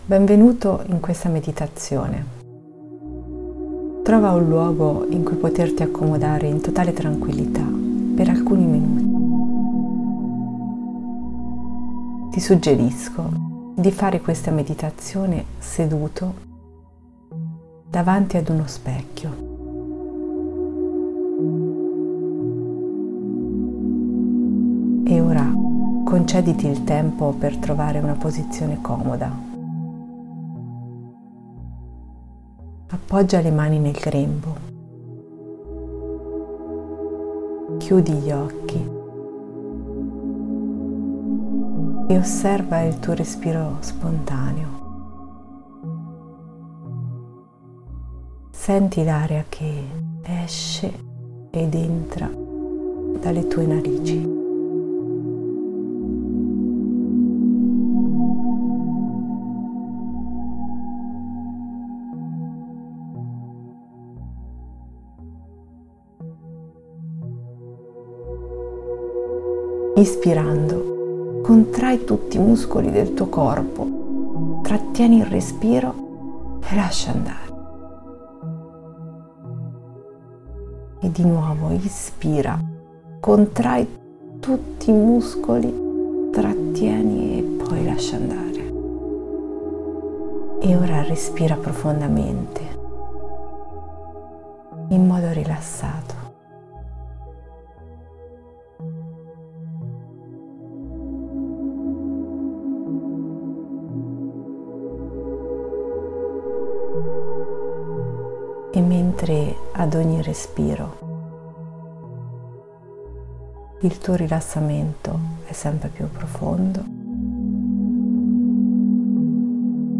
Qui sotto trovi la meditazione guidata “Io perdono me stesso”
Genitori-e-Figli-felici-meditazione-Io-perdono-me-stesso-432hrz.mp3